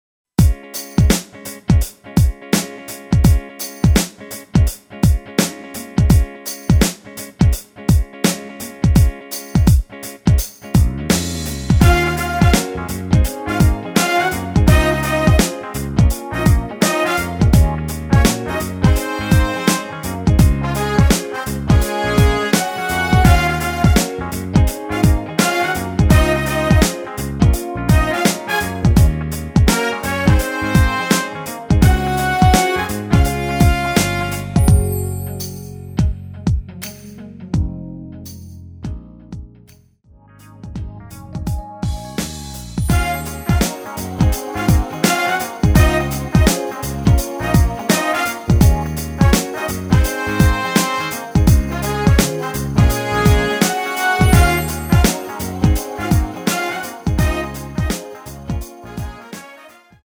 엔딩이 페이드 아웃 이라 엔딩 부분 만들어 놓았습니다.
Db
앞부분30초, 뒷부분30초씩 편집해서 올려 드리고 있습니다.
중간에 음이 끈어지고 다시 나오는 이유는